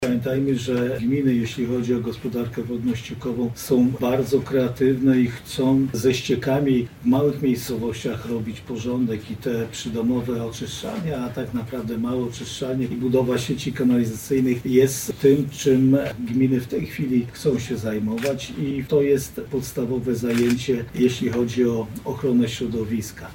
O tym jaki wpływ na gminy będzie miało to dofinansowanie, mówi Marszałek Województwa Lubelskiego Jarosław Stawiarski: